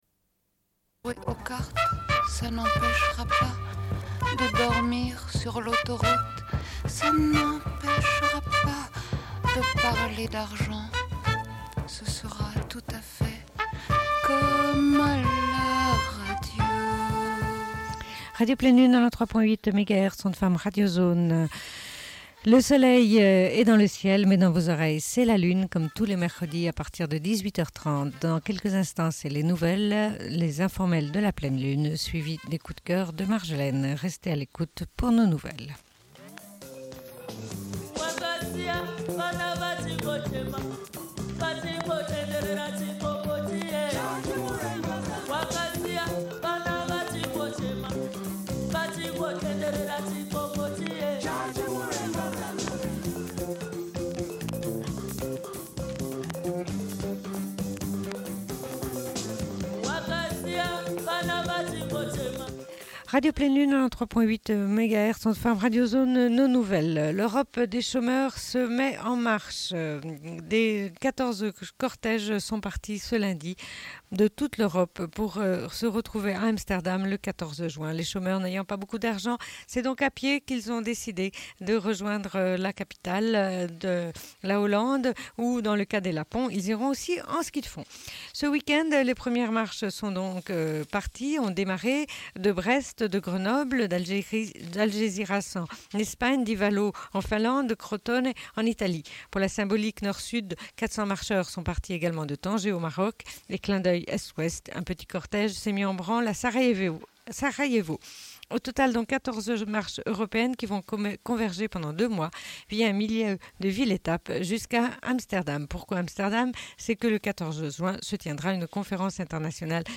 Bulletin d'information de Radio Pleine Lune du 16.04.1996 - Archives contestataires
Une cassette audio, face B